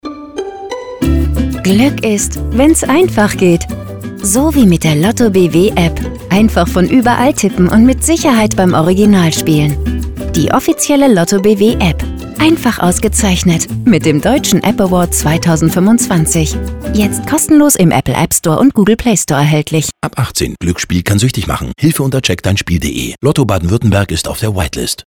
FEMALE GERMAN VOICE ARTIST and Voice Actress
My personal recording studio allows your audio file to be recorded very easily and guarantees the highest audio quality.